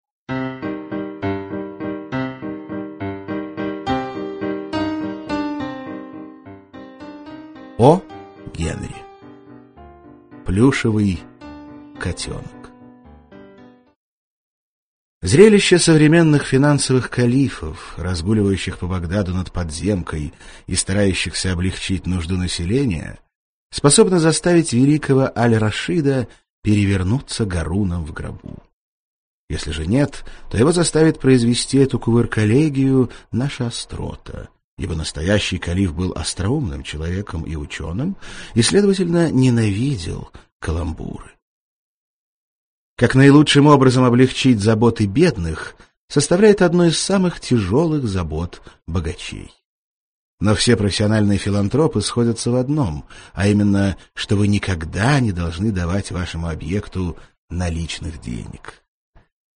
Аудиокнига Плюшевый котенок | Библиотека аудиокниг